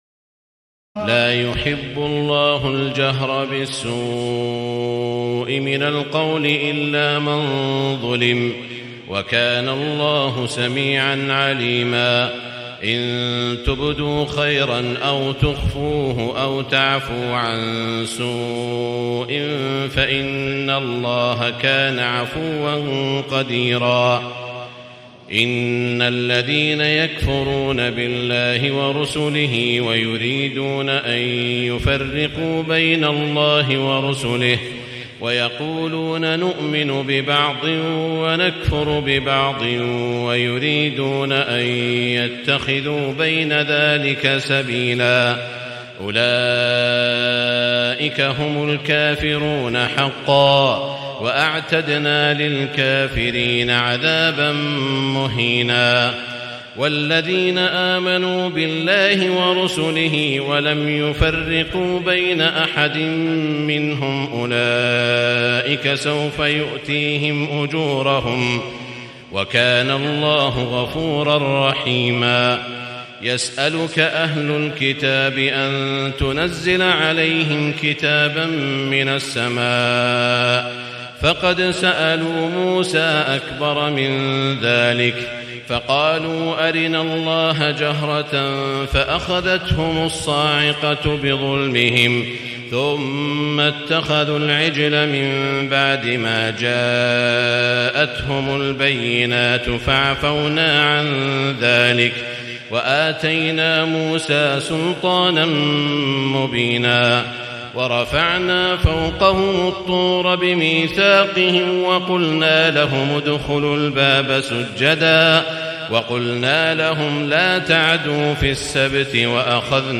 تهجد ليلة 26 رمضان 1437هـ من سورتي النساء (148-176) و المائدة (1-40) Tahajjud 26 st night Ramadan 1437H from Surah An-Nisaa and AlMa'idah > تراويح الحرم المكي عام 1437 🕋 > التراويح - تلاوات الحرمين